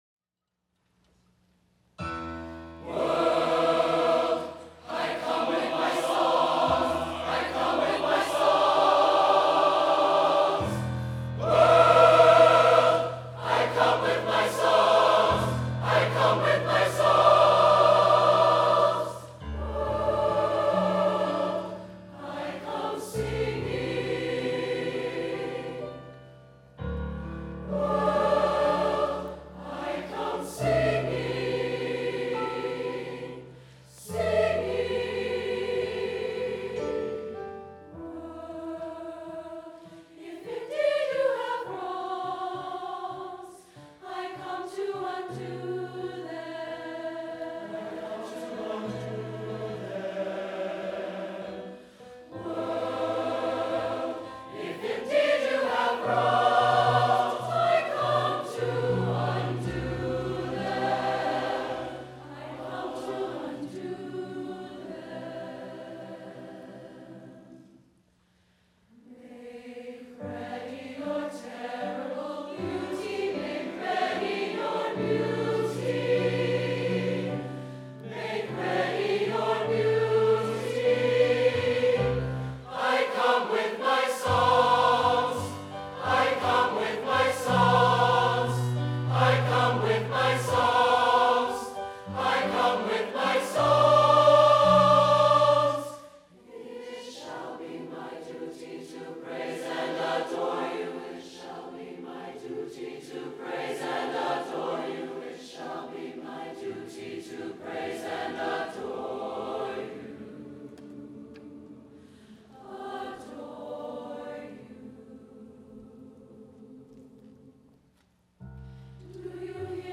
SATB, piano